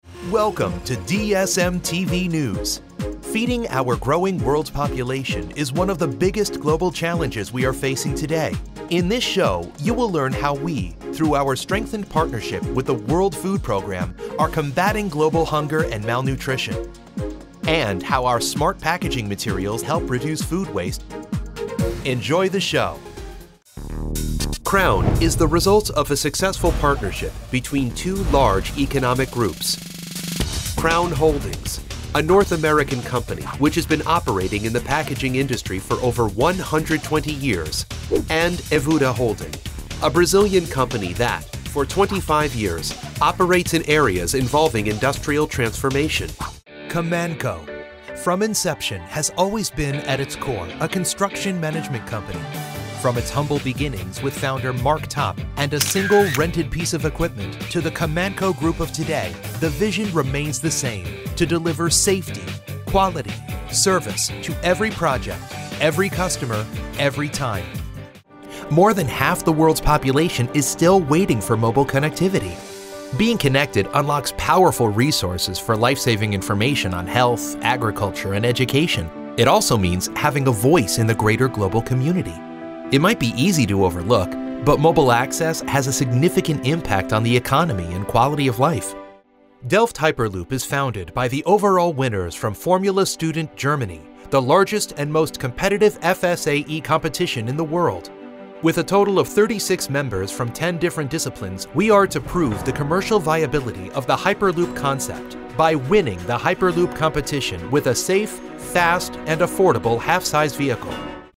Inglés (Americano)
Comercial, Travieso, Seguro, Amable, Cálida
Corporativo